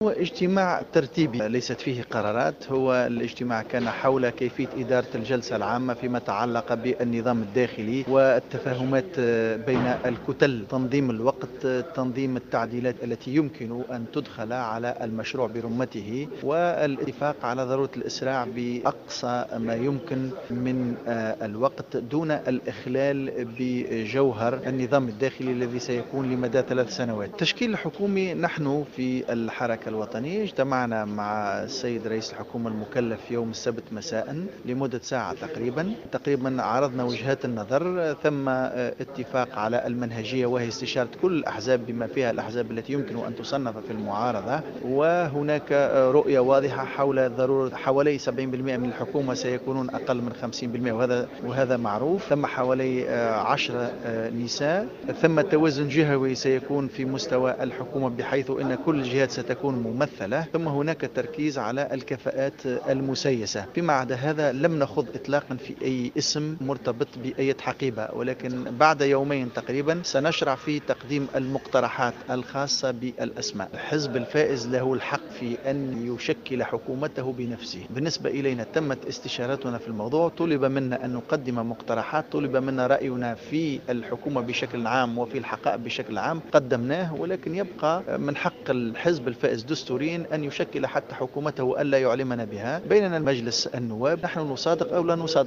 قال نائب مجلس الشعب،التوهامي العبدولي على هامش اجتماع لرؤساء الكتل والمجموعات النيابية انعقد اليوم بمقر مجلس نواب الشعب إن الحكومة القادمة ستضم 10 نساء.